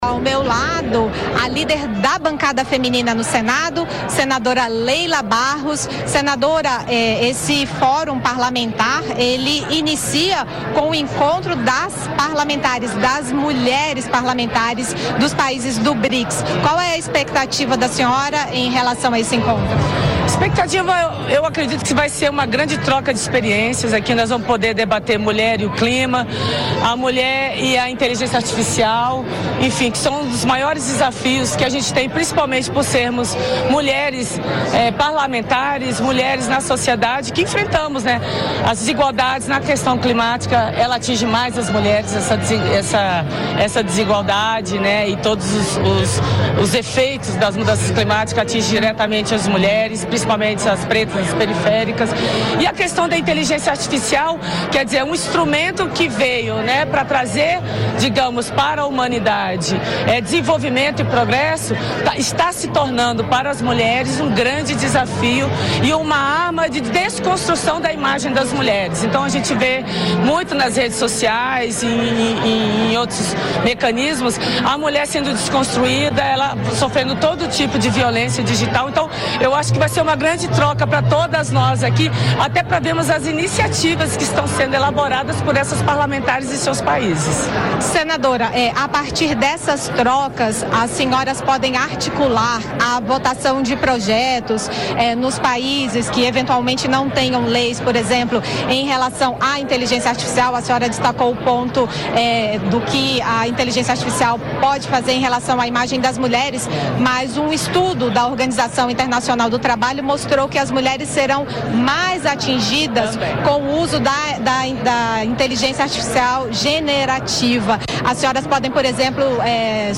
A senadora Leila Barros (PDT-DF), líder da bancada feminina do Senado, falou sobre as expectativas para o 11º Fórum Parlamentar do Brics. Leila ressaltou que as mulheres são as mais atingidas pelas mudanças climáticas e pelo desenvolvimento da inteligência artificial, e que a participação das parlamentares dos países do Brics na discussão desses temas é fundamental.